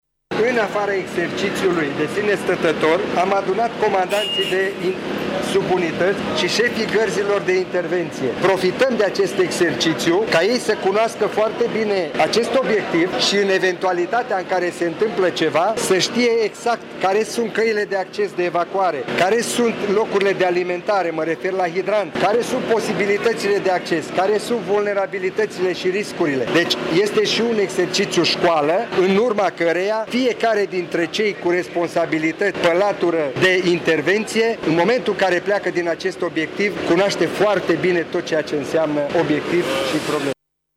Şeful ISU, col Dorin Oltean, a explicat că exerciţiul are rolul de a familiariza pompierii cu obiectivul: